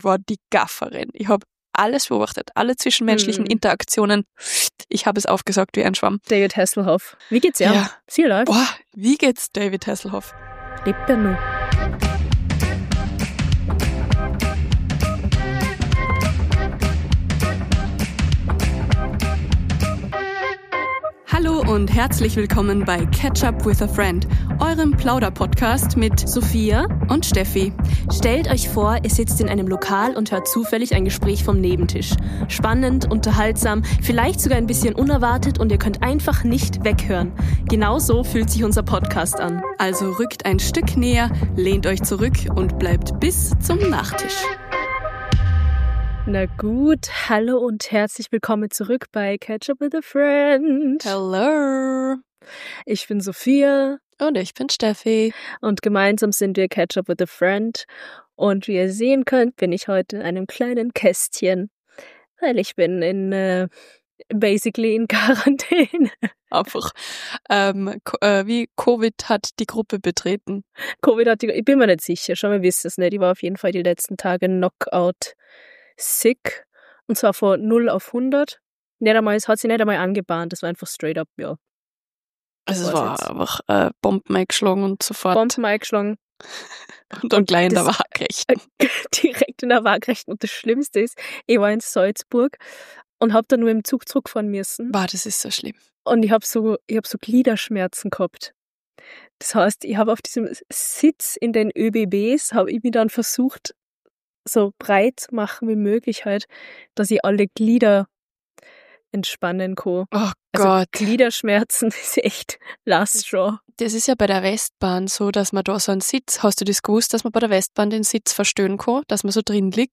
Eine etwas chaotische Remote-Episode! Wir analysieren Menschen in boujee Hotels, erzählen von Trash-TV-Party-Beobachtungen und fragen uns, warum uns der Weihnachtsdruck jedes Jahr so zerlegt.